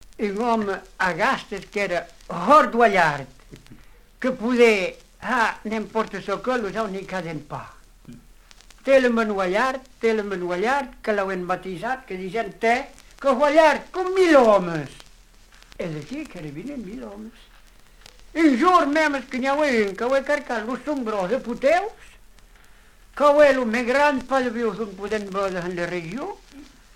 Genre légende
Catégorie Récit